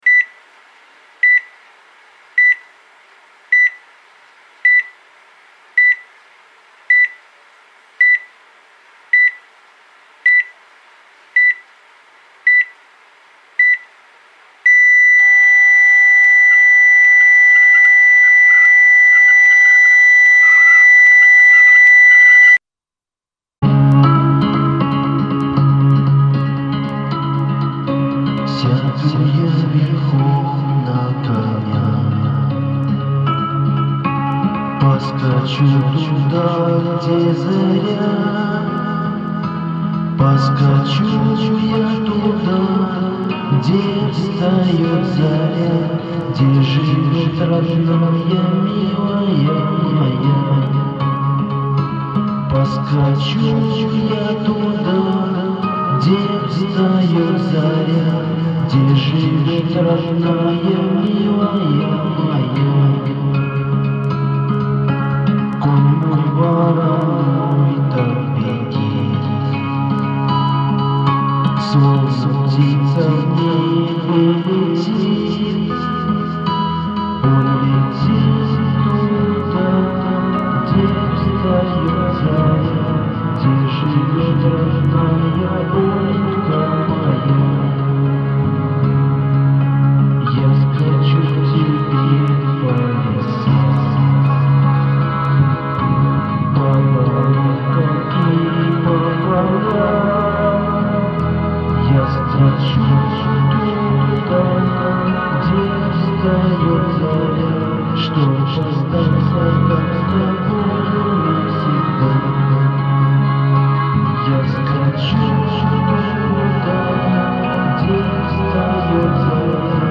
254vokal_tekst_Gde_vstaet_zarjagolos_izmenen-kolonochnaya_versiya.mp3